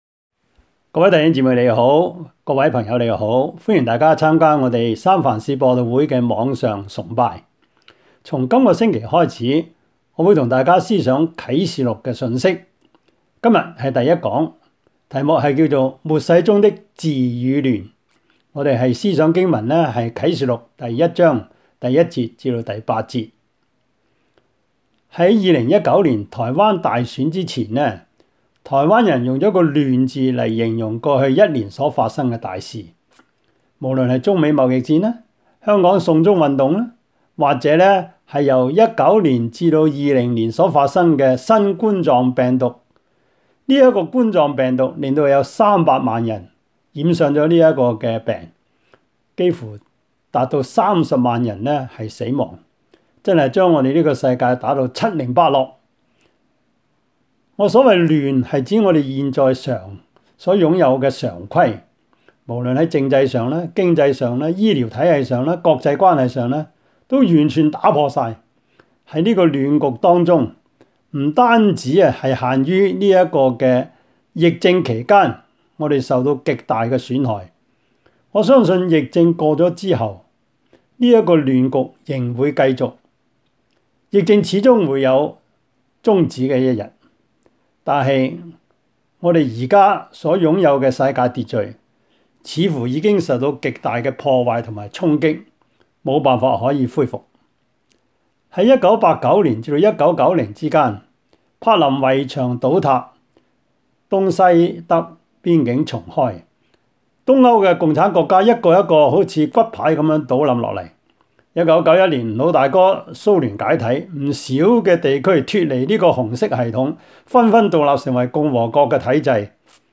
Passage: 啟示錄 1:1-8 Service Type: 主日崇拜
Topics: 主日證道 « 從恐懼到相信 立定根基 »